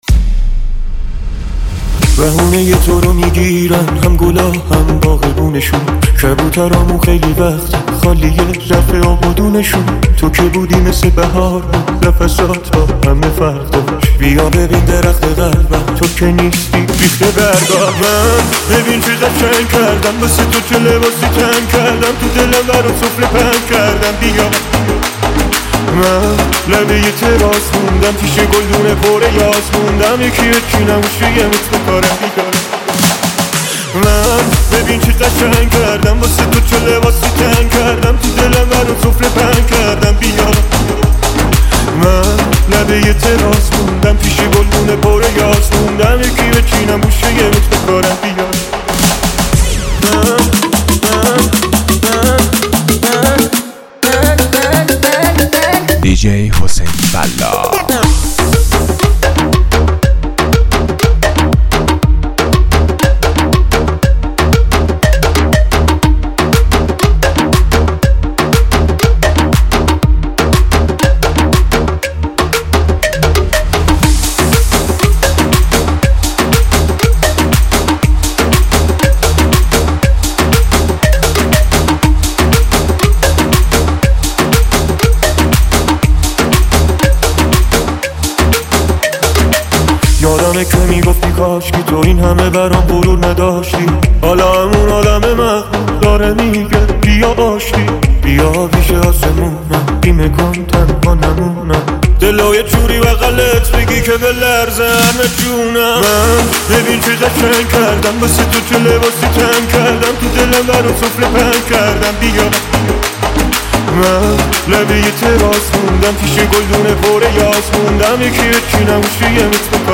آهنگهای پاپ فارسی
ریمیکس جدید